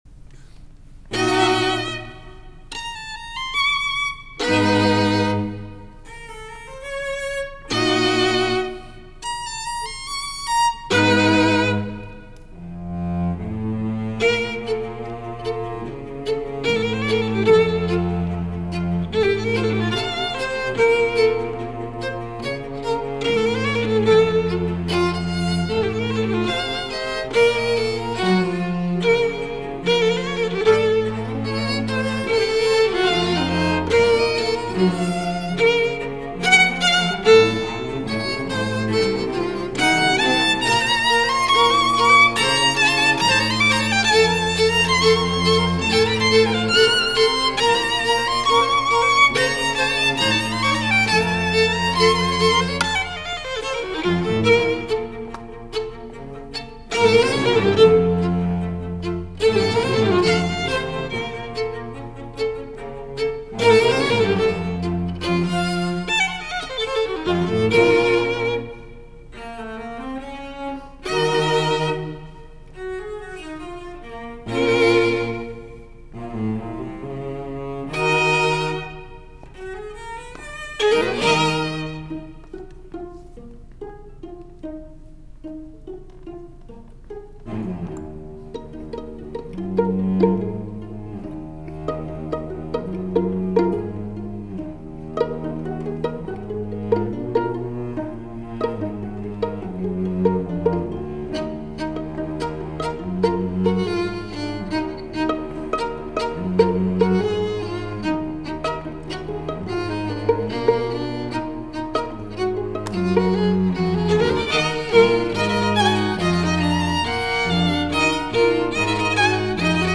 Original String Quartet
This is a short composition for two violins, a viola, and a cello, loosely based on the sonata form.
The Cuarteto Latinoamericano performed a read-through of the piece on March 25th, 2019. As it was only a sight reading (so they did not rehearse the piece beforehand), it is naturally not a 100% accurate representation of the score, however they were still surprisingly accurate and it sounded great.
string-quartet-performance.mp3